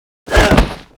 WAV · 86 KB · 單聲道 (1ch)